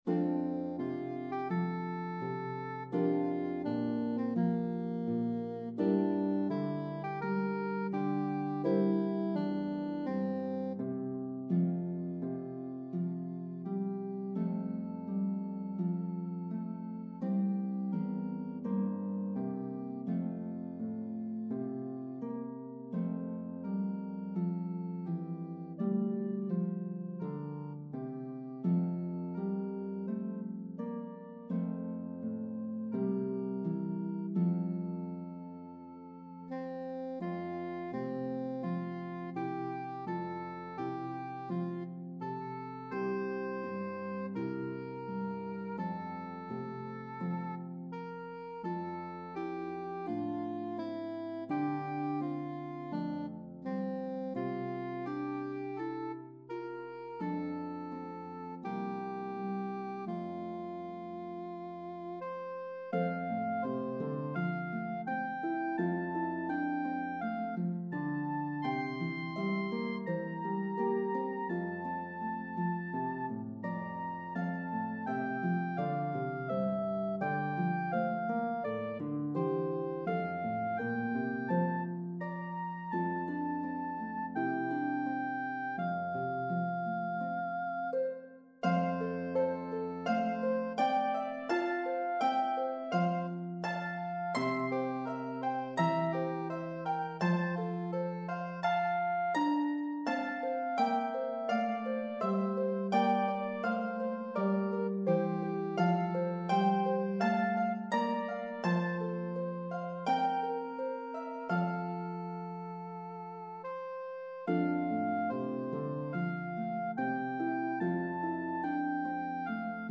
Harp and Soprano Saxophone version